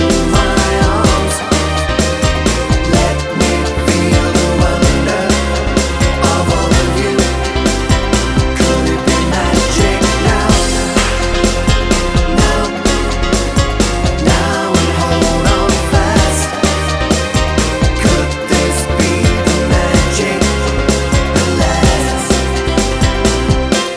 No Backing Vocals Pop